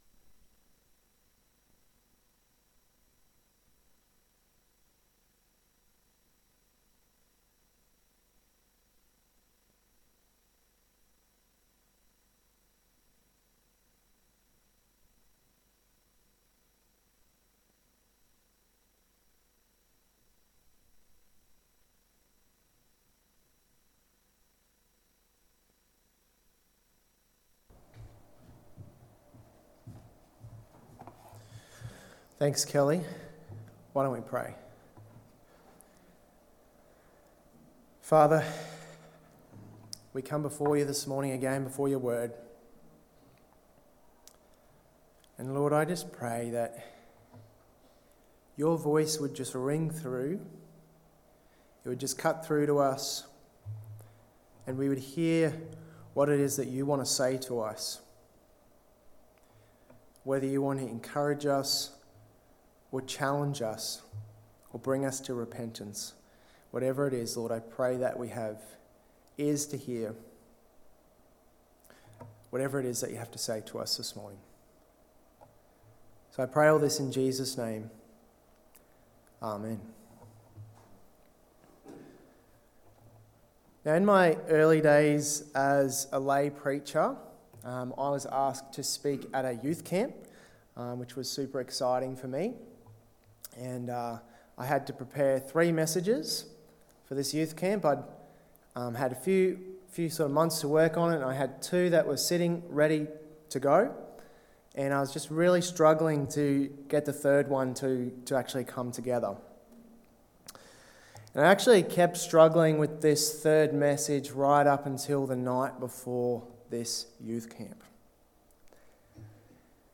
Sermons | Tenthill Baptist Church